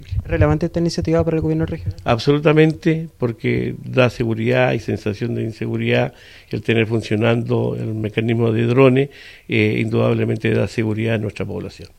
Consultado por La Radio, el gobernador Luis Cuvertino explicó que el traspaso desde la Dirección de Presupuestos del Ministerio de Hacienda no se ha concretado, situación que atribuyó a la escasez de recursos a nivel central.